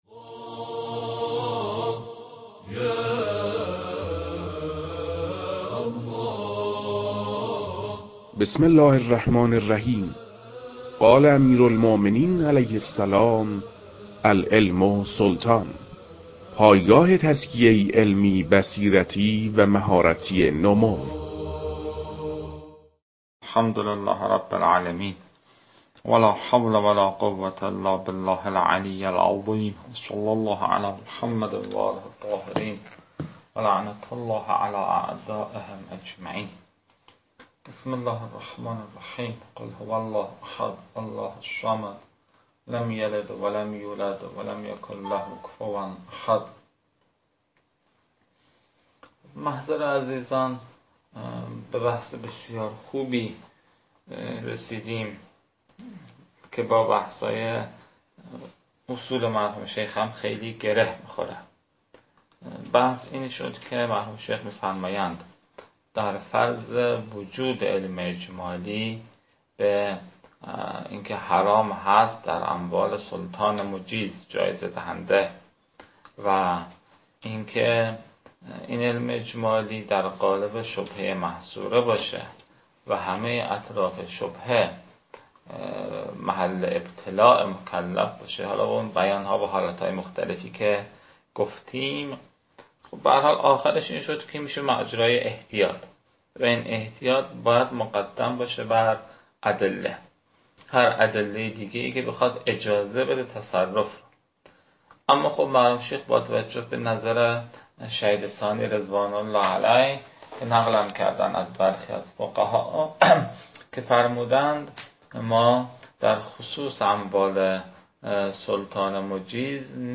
فایل های مربوط به تدریس مبحث المسألة الثانية جوائز السلطان و عمّاله